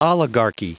Prononciation du mot oligarchy en anglais (fichier audio)
Prononciation du mot : oligarchy